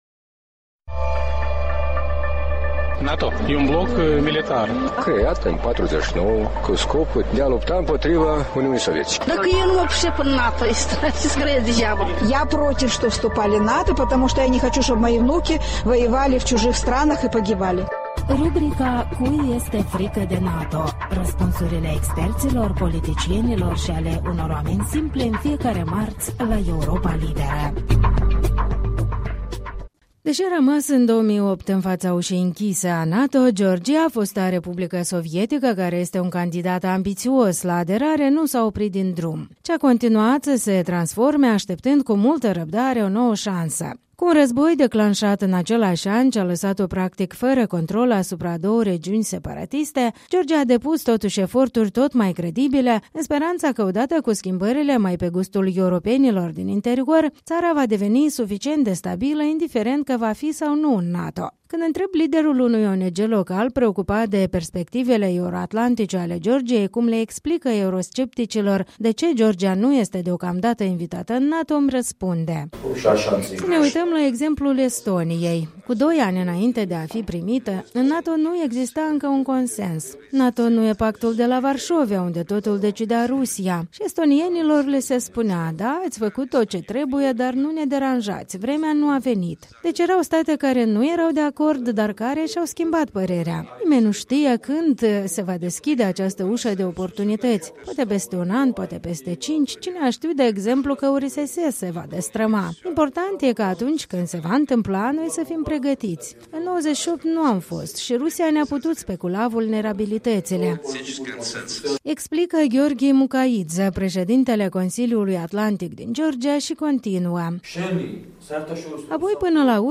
De vorbă cu activiști și oficialități de la Tbilisi despre stereotipurile integrării euro-atlantice.